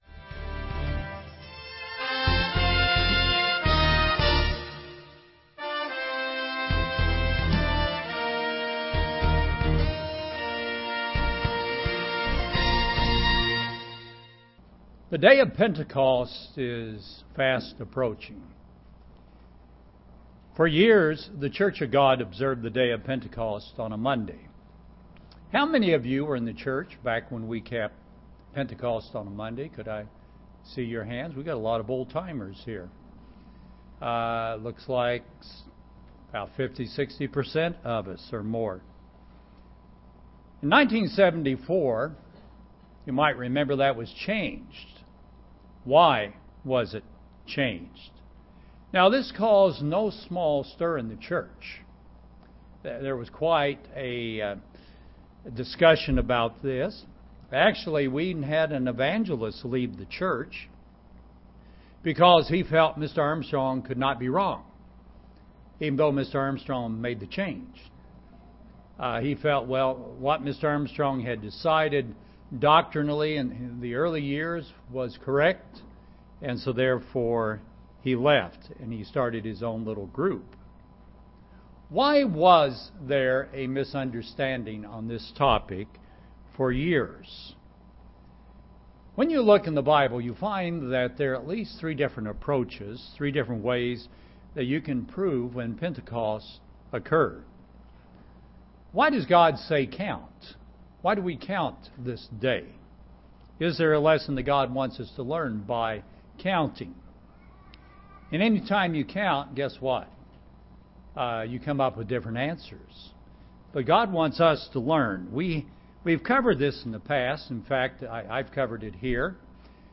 In this sermon we will take a look at the technical side of how to answer these questions.